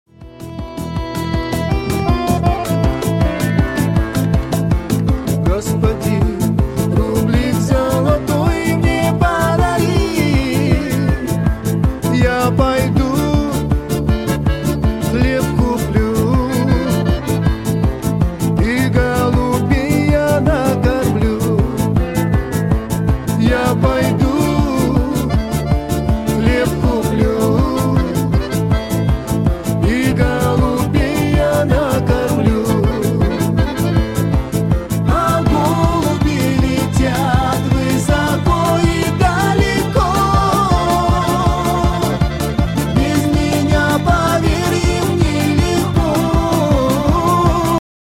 • Качество: 128, Stereo
цыганские